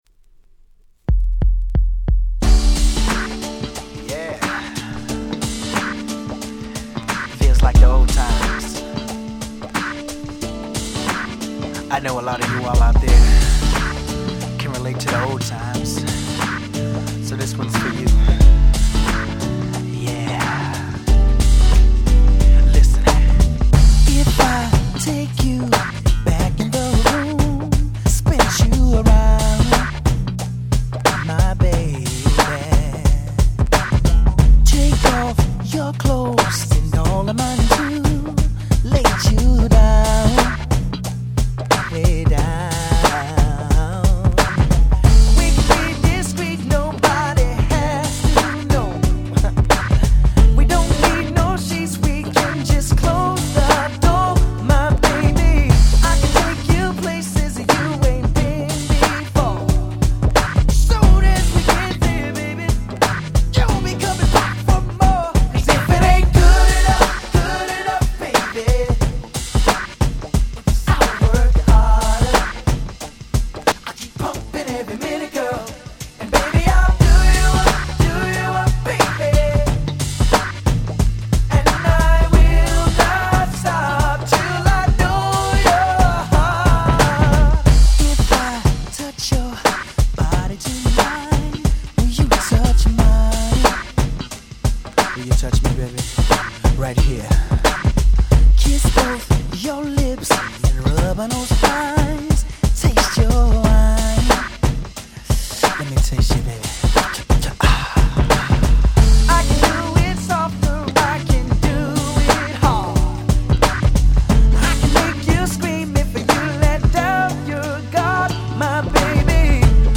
92' Smash Hit R&B !!
この時期の彼の楽曲にしては珍しく、本作はイケイケなNew Jackナンバーではなく、まったりとした甘いMidナンバー。